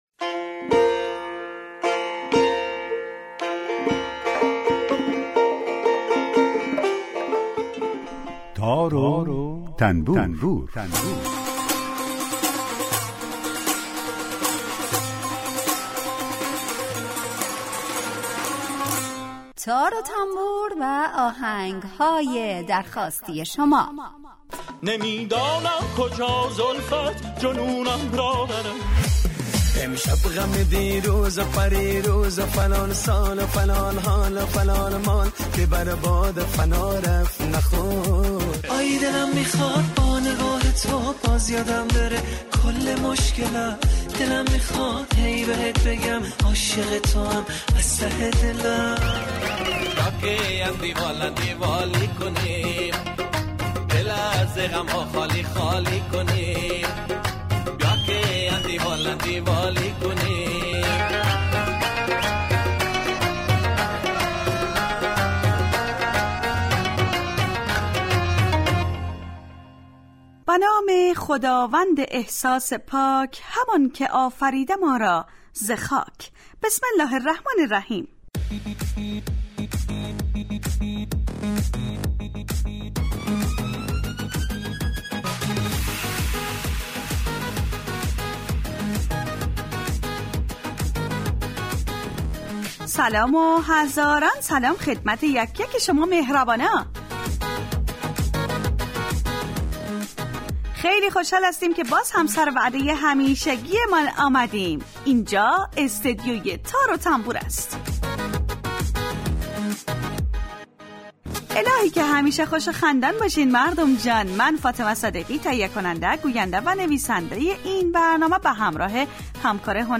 برنامه تار و تنبور هر روز از رادیو دری به مدت 40 دقیقه برنامه ای با آهنگ های درخواستی شنونده ها کار از گروه اجتماعی رادیو دری.
در این برنامه هر یه آیتم به نام در کوچه باغ موسیقی گنجانده شده که به معرفی مختصر ساز ها و آلات موسیقی می‌پردازیم و یک قطعه بی کلام درباره همون ساز هم نشر میکنیم